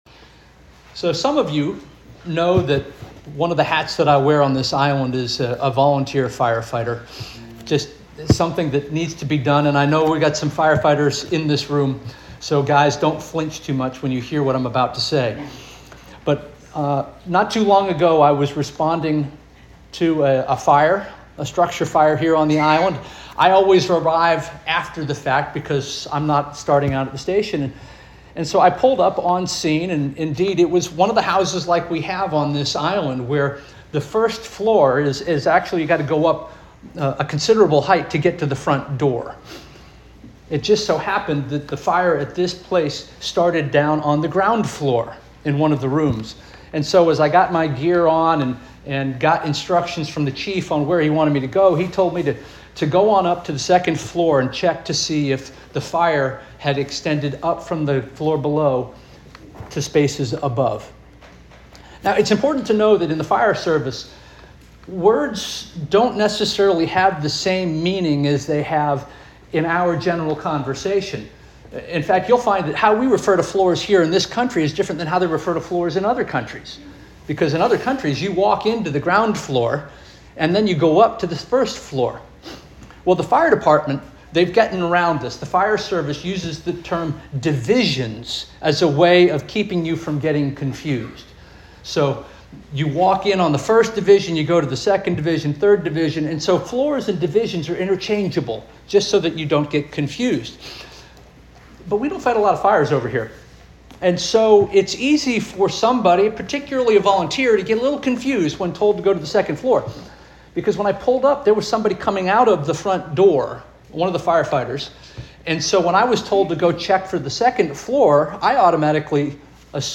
September 21 2025 Sermon - First Union African Baptist Church